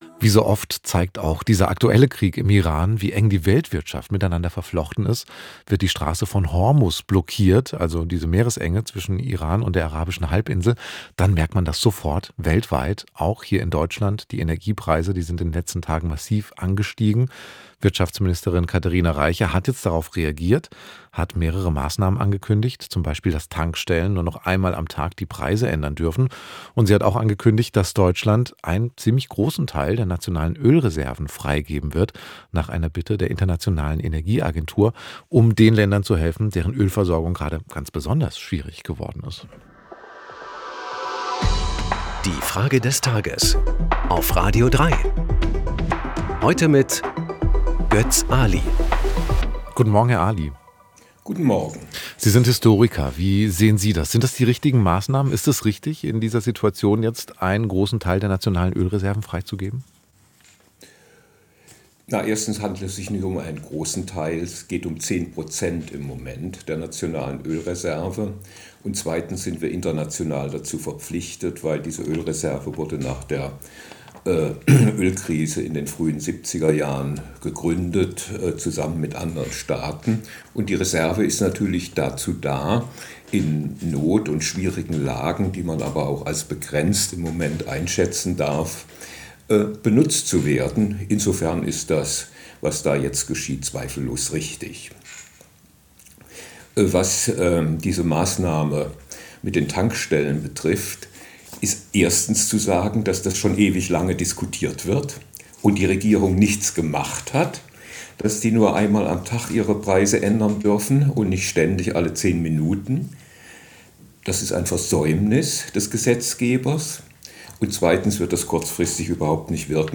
Zehn starke Stimmen im Wechsel "Die Frage des Tages" – montags bis freitags, immer um 8 Uhr 10.